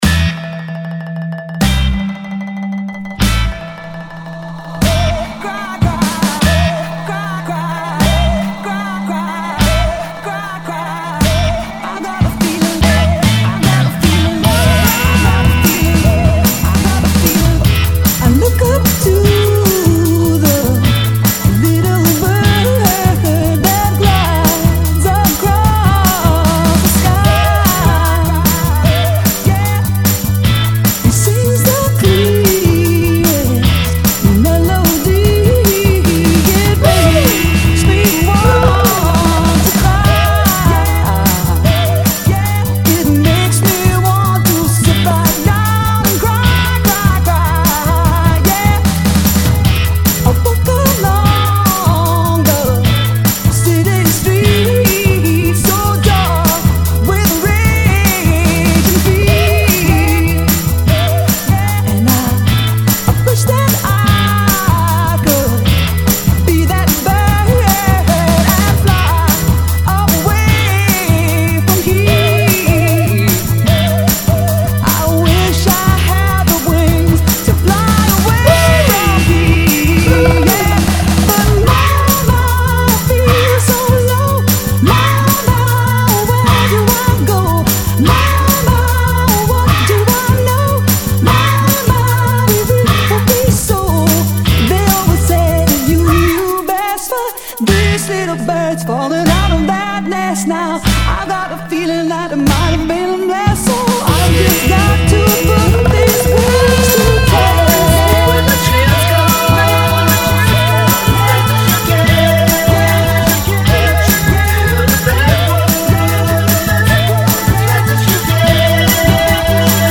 Bootlegs (page 12):
Instrumentale
acapella .